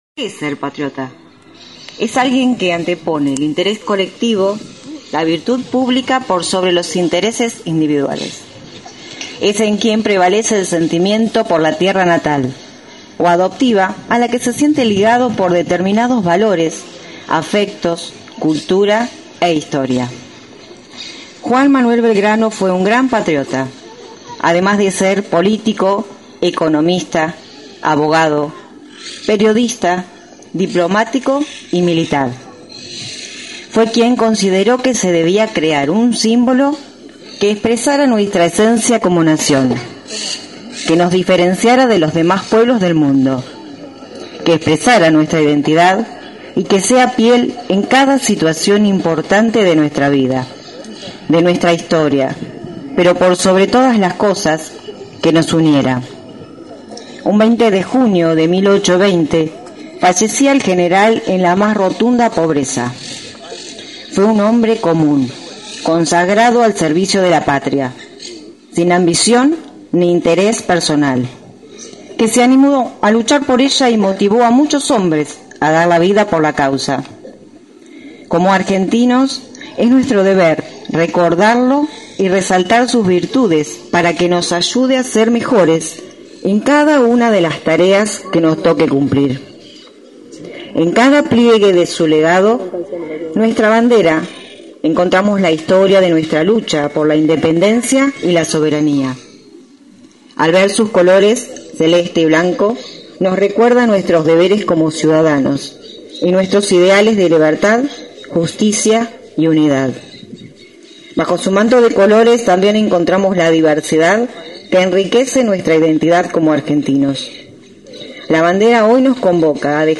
Luego se escuchó una reflexión de la docente